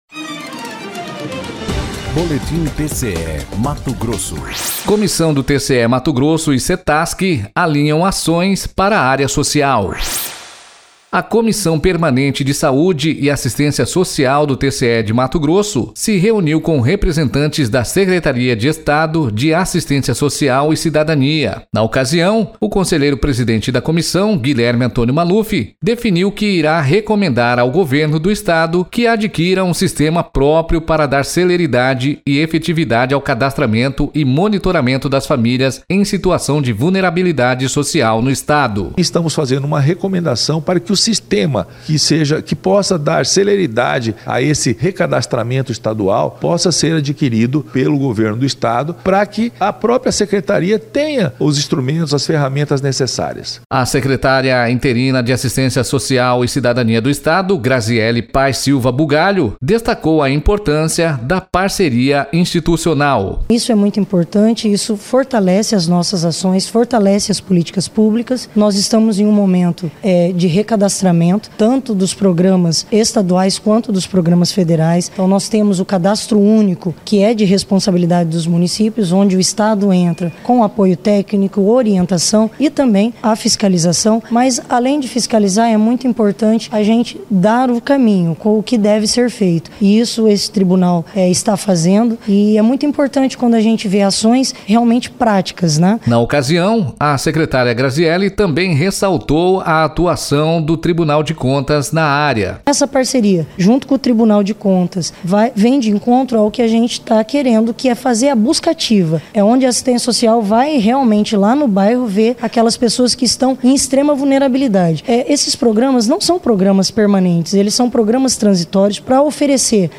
Sonora: Guilherme Antonio Maluf – conselheiro presidente Comissão Permanente de Saúde e Assistência Social do TCE-MT
Sonora: Grasielle Paes Silva Bugalho - secretária interina de Assistência Social e Cidadania de MT